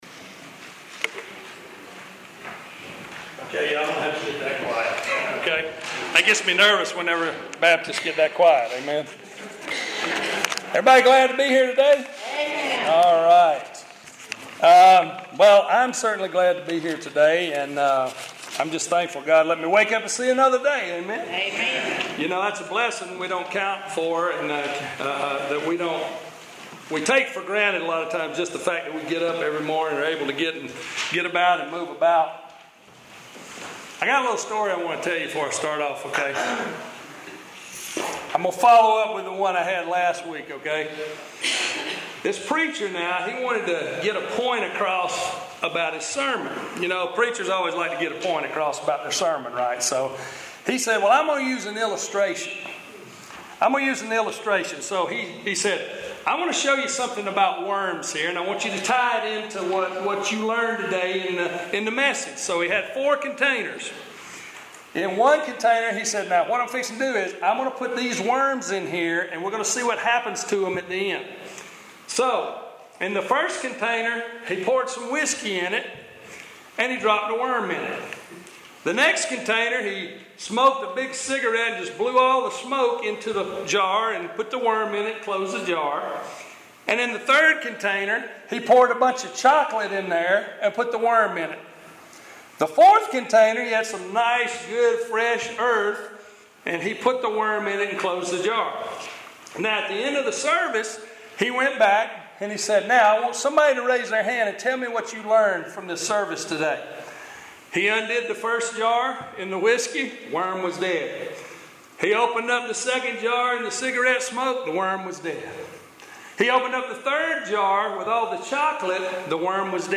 Bethesda Baptist Church Sermons Page